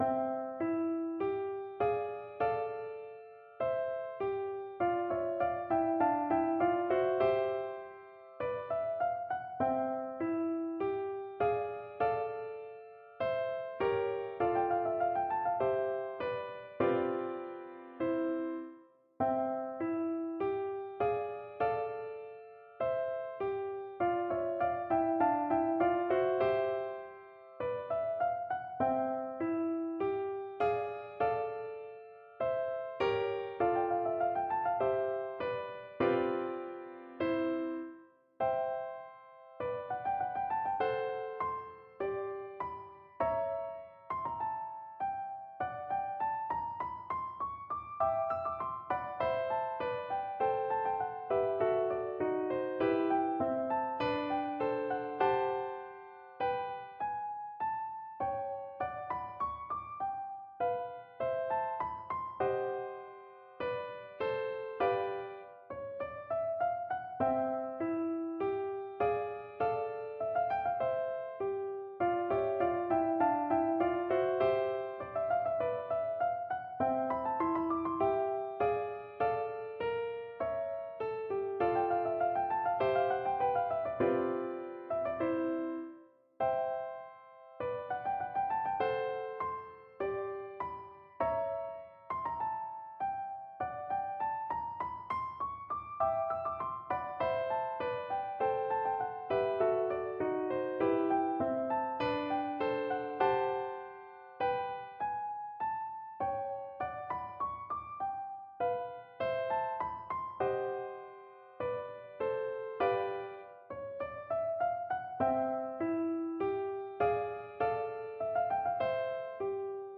No parts available for this pieces as it is for solo piano.
2/2 (View more 2/2 Music)
Arrangement for Piano
Classical (View more Classical Piano Music)